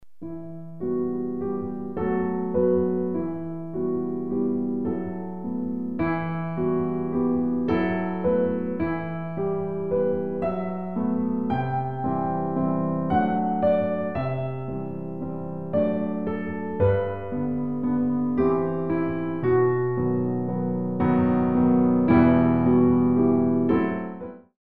All piano CD for Pre- Ballet classes.